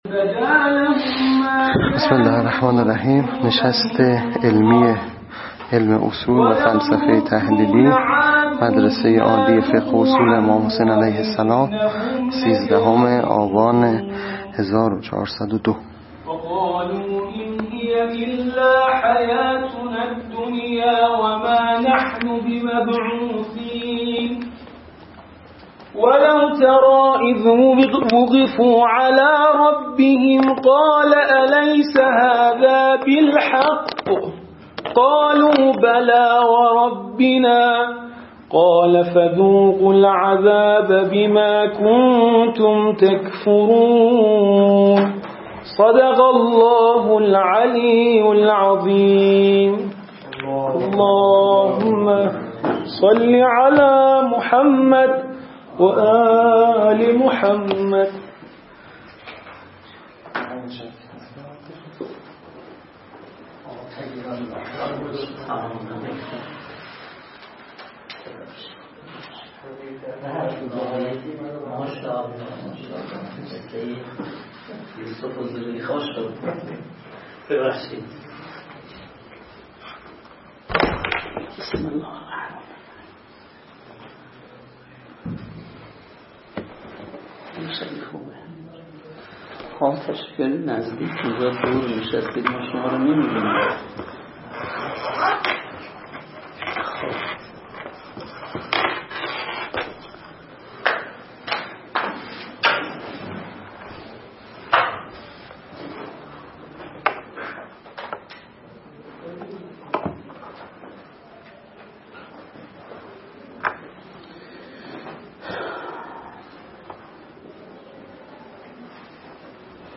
نشست علمی